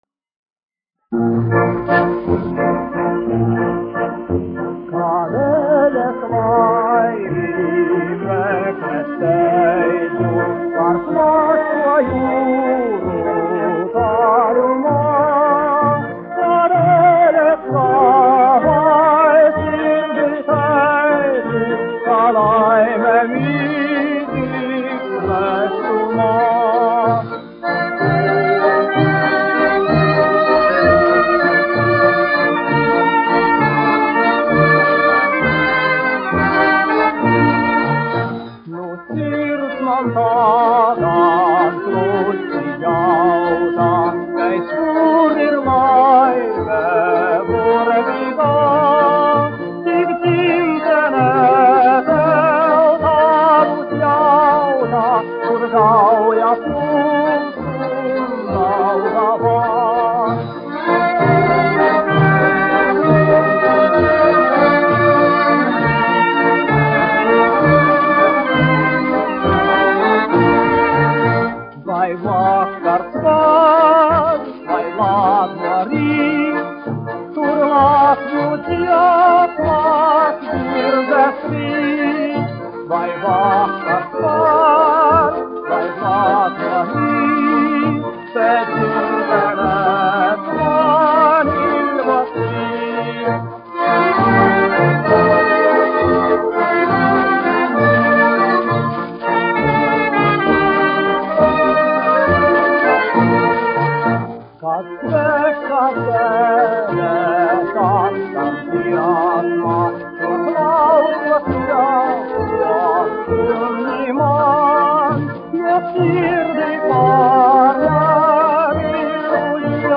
1 skpl. : analogs, 78 apgr/min, mono ; 25 cm
Populārā mūzika -- Latvija
Skaņuplate